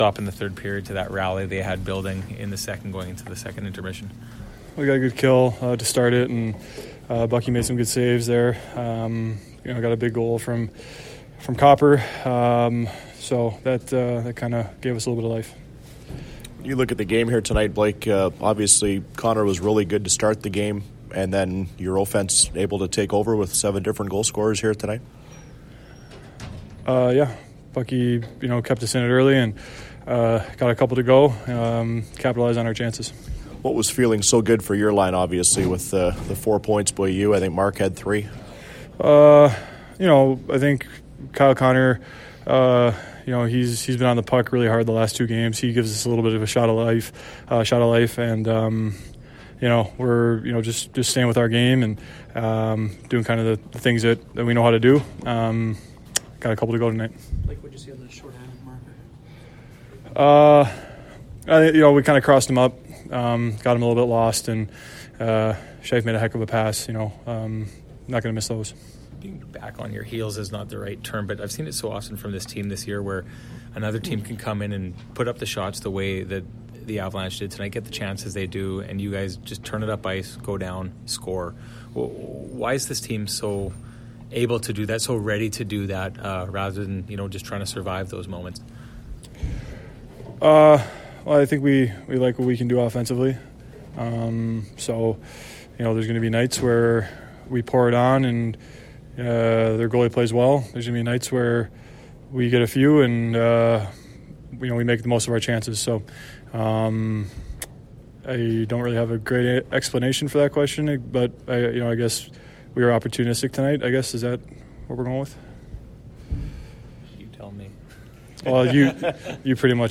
January-8-2019-Blake-Wheeler-post-game.mp3